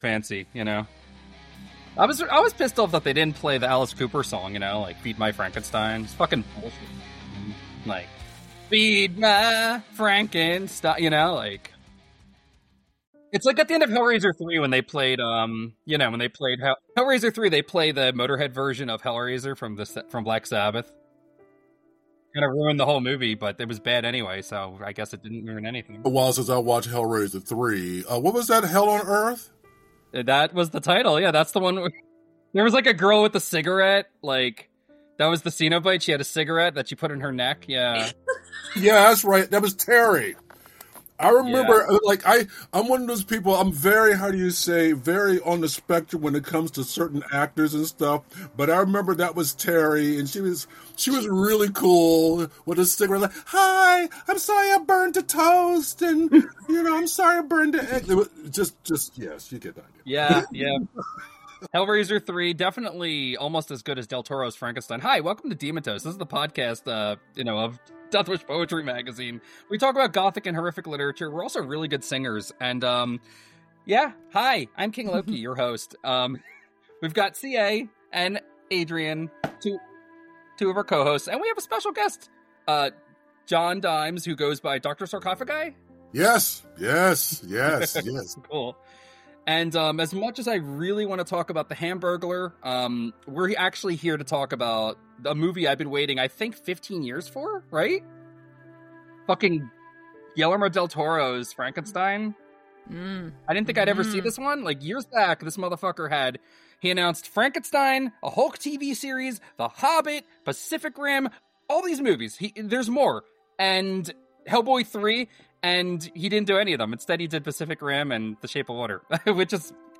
Join us as we dive into a lively discussion on Guillermo del Toro's 'Frankenstein'! We explore the film's gothic themes, the emotional depth of the monster, and the stunning visual storytelling that del Toro is known for. Our conversation touches on the film's impact on gothic cinema and its reflection on creation and humanity.